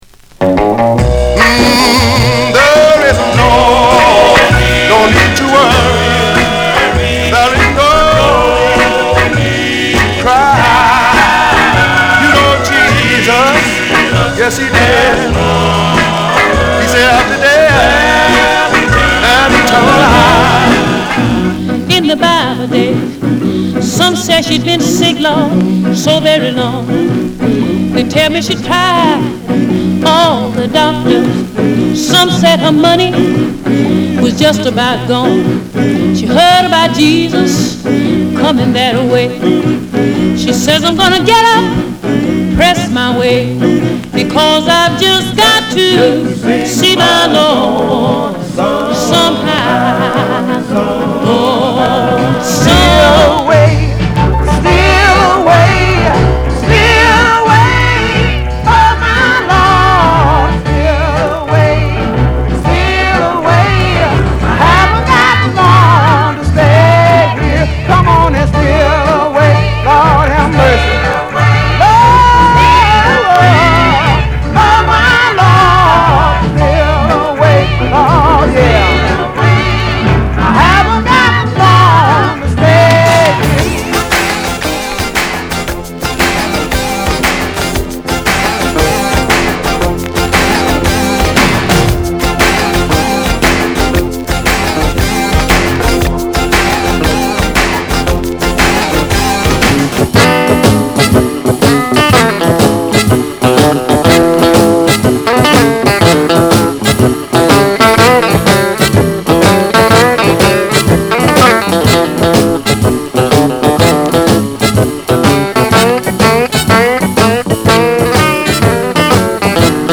R&B、ソウル